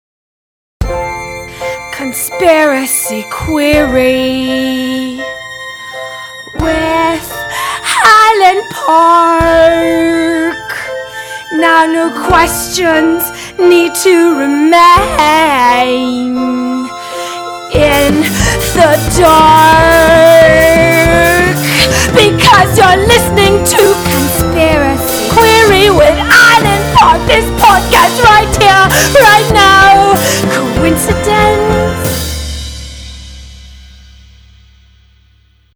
Next day I thought that Bjork singing the Conspiracy Query theme song would be much more hilarious and appropriately fishy (it is a conspiracy podcast after all).